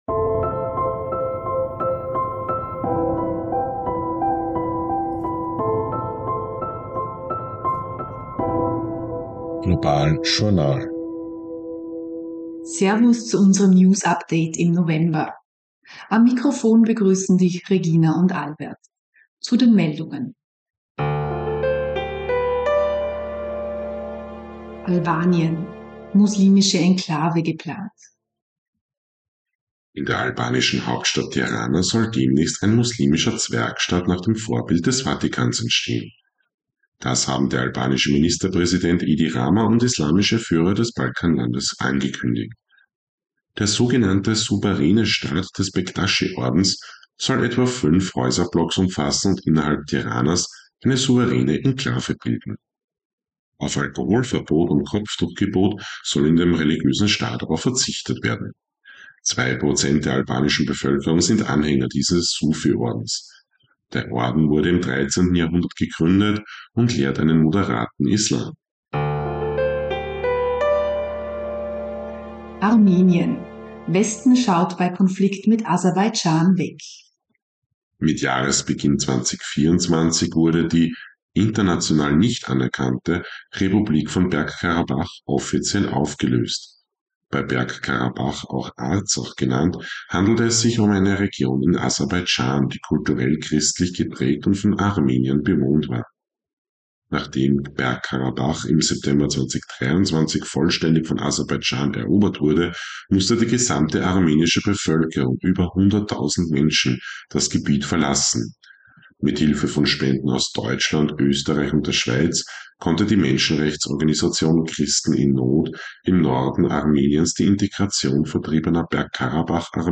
News Update November 2024